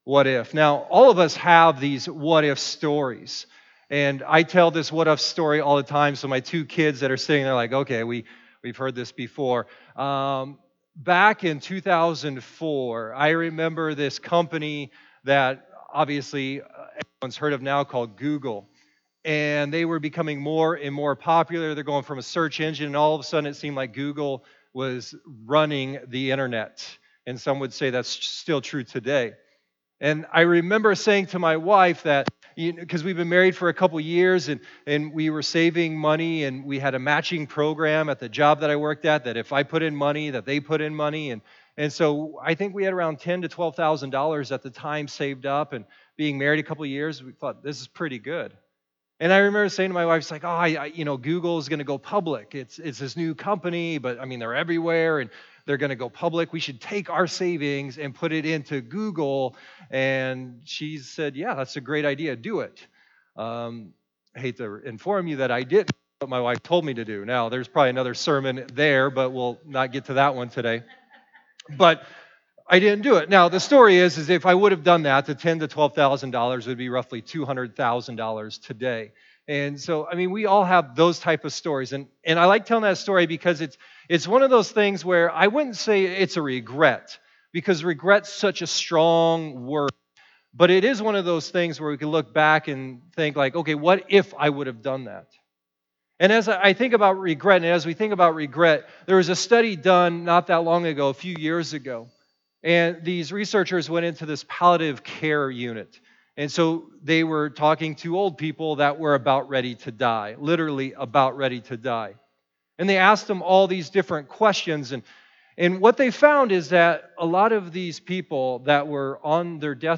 Sermons | Westgate Alliance Church